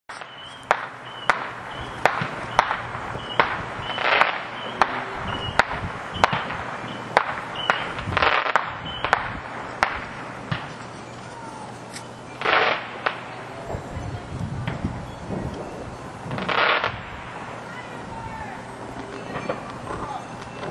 Sounds of the northwest suburbs in the seconds after midnight, New Year’s Day 2025.